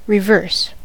reverse: Wikimedia Commons US English Pronunciations
En-us-reverse.WAV